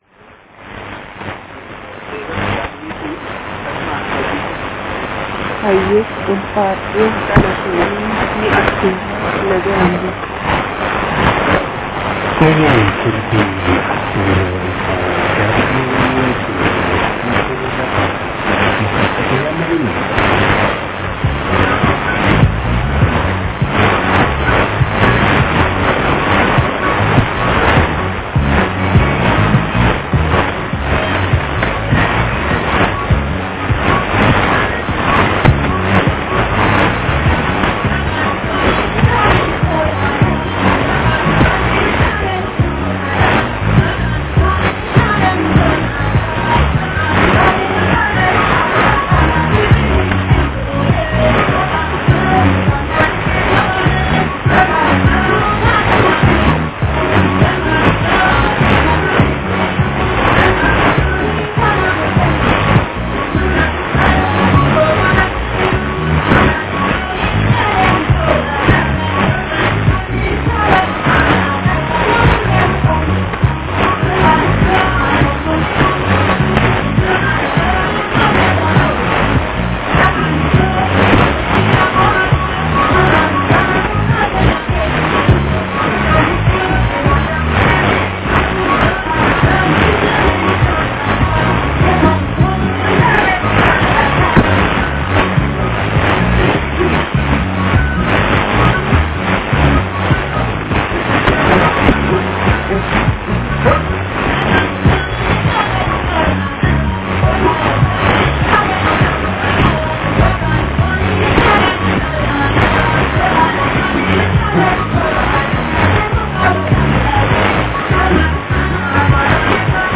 先月30日05時台、HOA音楽が聞こえていました。
エチオピアからのHOA音楽はエキゾチックで最高です♪
<受信地：東京都江東区新砂 東京湾荒川河口 RX:ICF-SW7600GR ANT:AN-12>